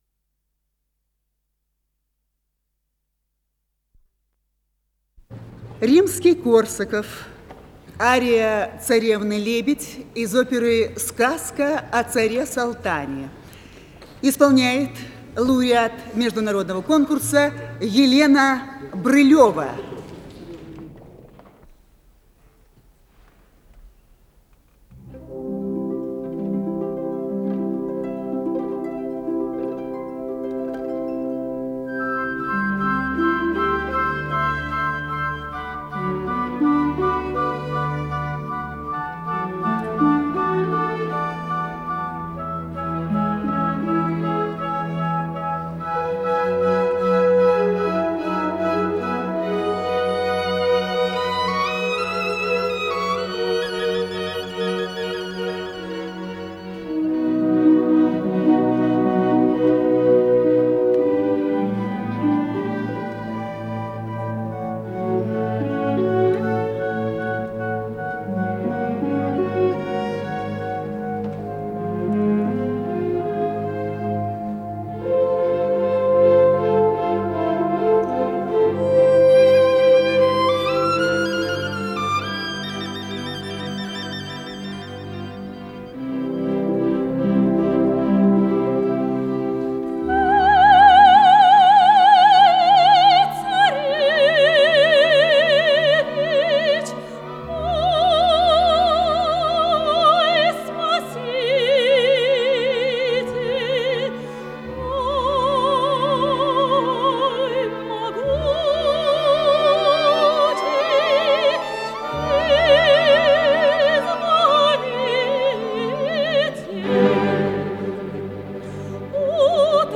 Запись из Колонного зала Дома Союзов от 10 февраля 1991г.
сопрано
АккомпаниментБольшой концертный оркестр Гостелерадио СССР